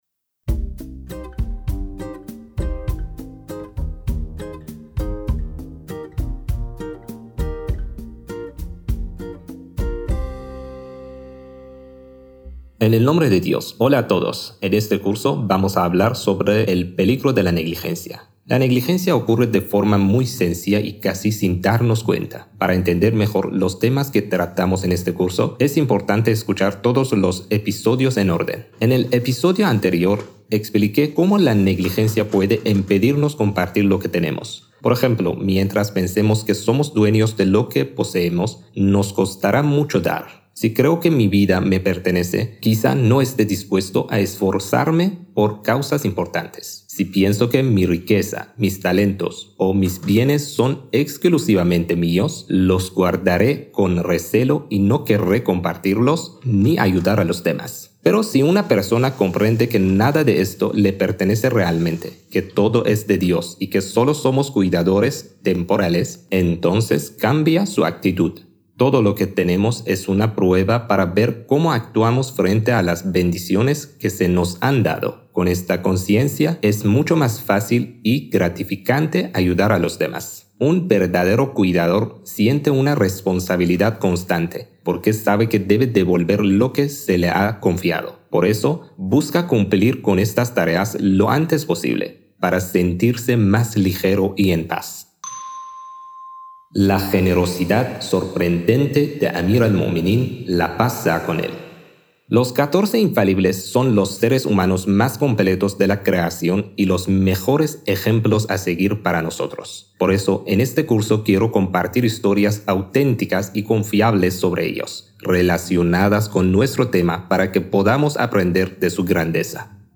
La Generosidad Sorprendente de Amir al-Muminin | El Peligro de la Negligencia | Lección 13